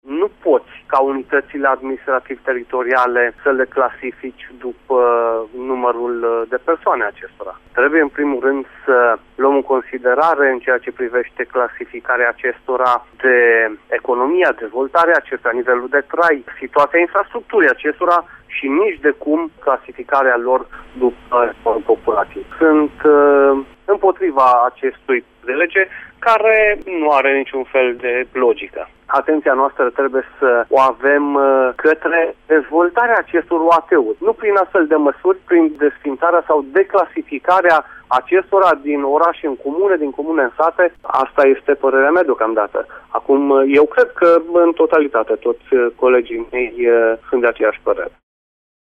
Liberalii resping această propunere, spune deputatul PNL de Caraş-Severin, Valentin Rusu.